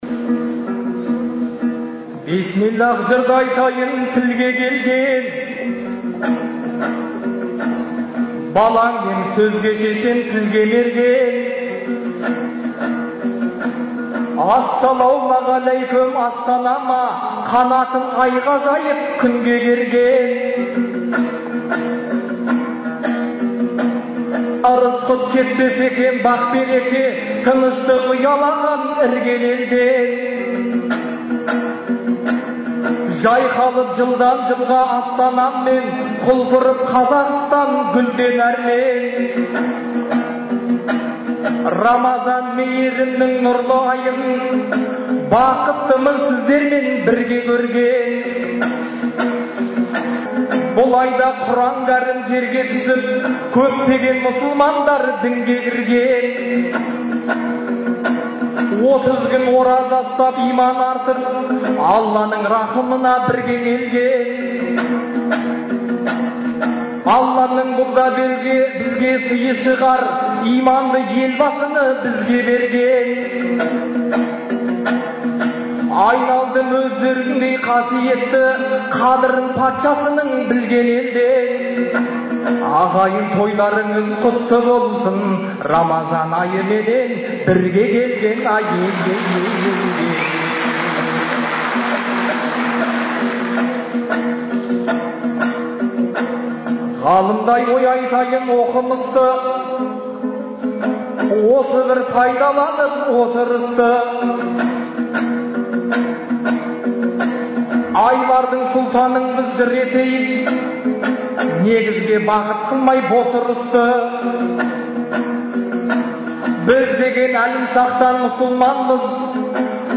Шілденің 8-9-ы күндері Астанадағы «Қазақстан» орталық концерт залында «Ел, Елбасы, Астана» деген атпен Астана күніне орай ақындар айтысы өткен. Айтыстың алғашқы күні 20 ақын сөз сайыстырды.